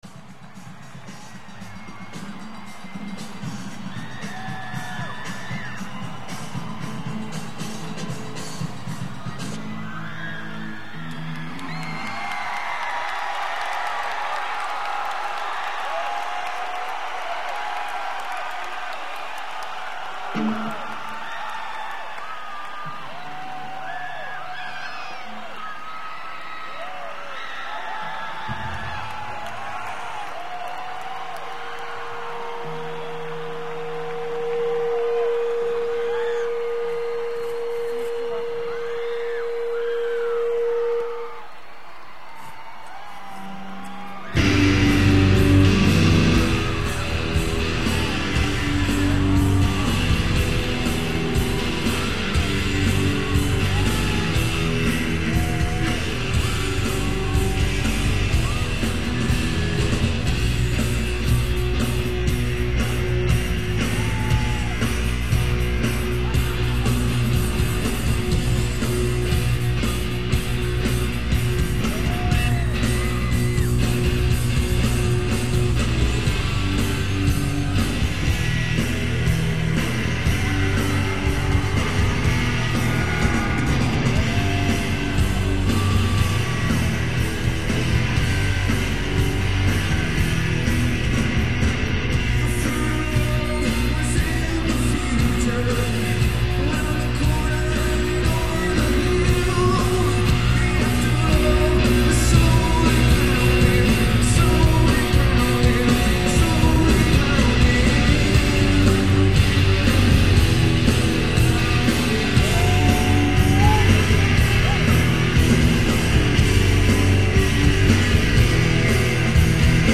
as performed in Milwaukee
at Marcus Amphitheatre, Milwaukee, WI